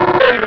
pokeemerald / sound / direct_sound_samples / cries / trapinch.aif
-Replaced the Gen. 1 to 3 cries with BW2 rips.